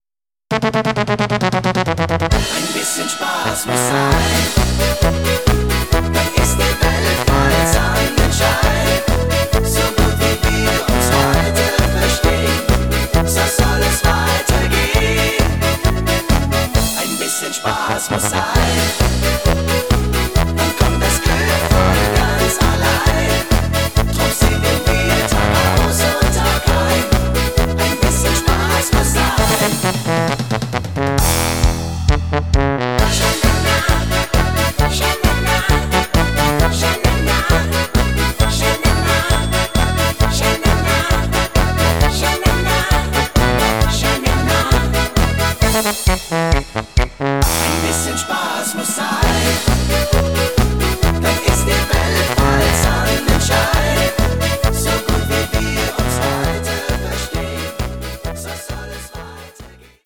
neue fetzige Version